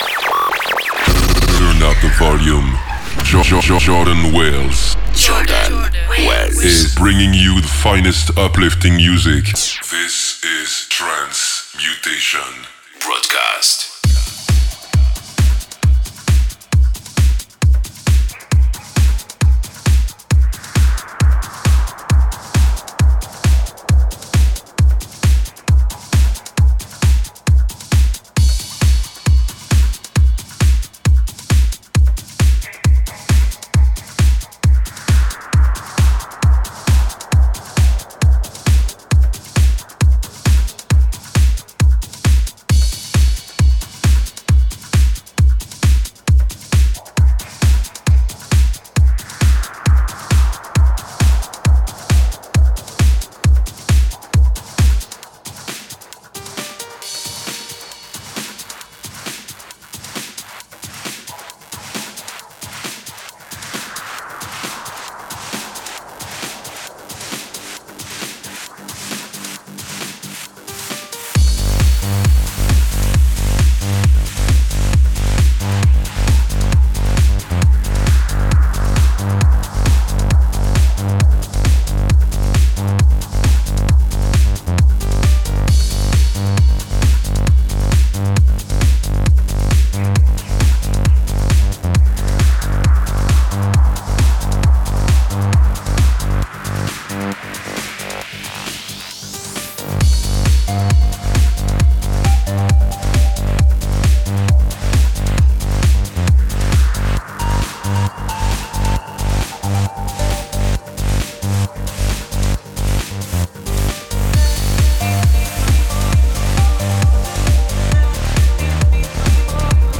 uplifting